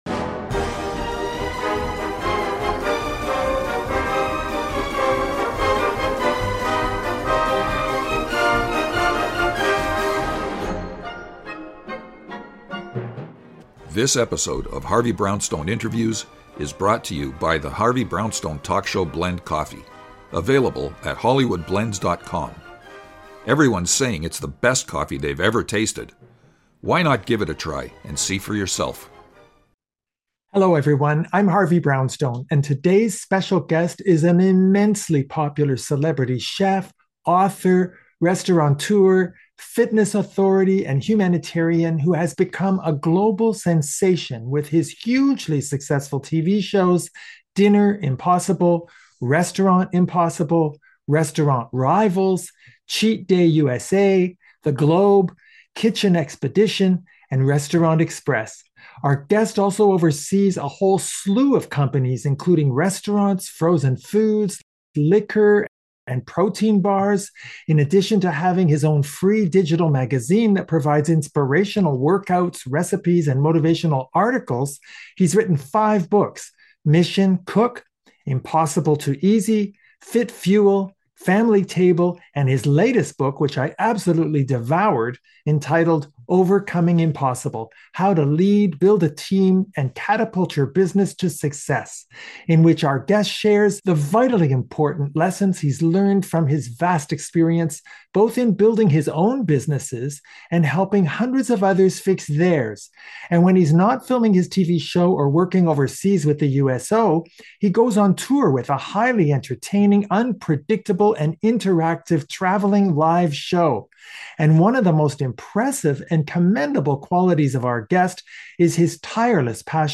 In-depth Interviews, of celebrities, authors and notable people who have made a significant difference in the world, by retired judge Harvey Brownstone